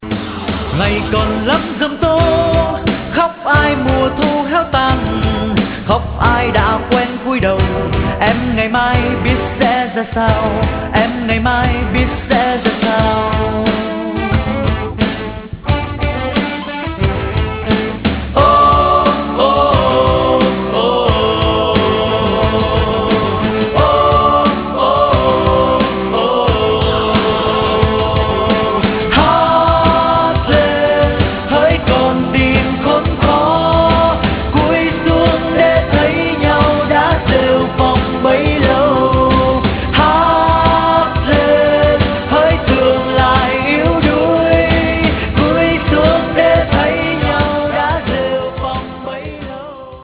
Pop/ Acoustic/ Indie